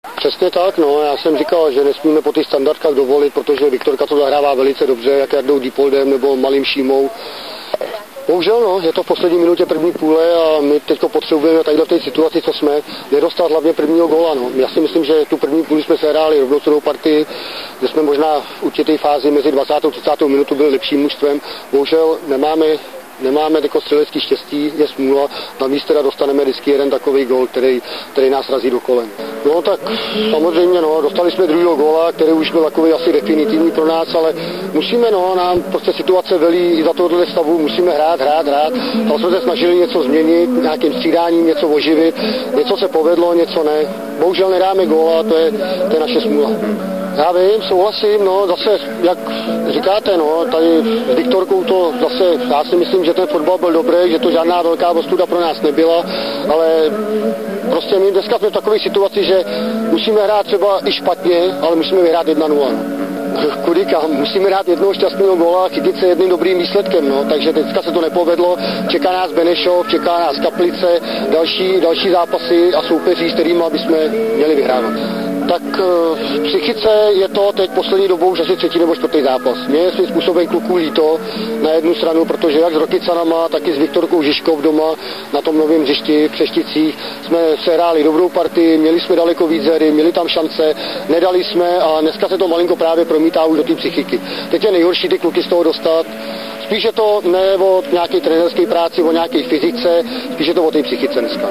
Přinášíme vám hodnocení obou zápasů prostřednictvím trenérů.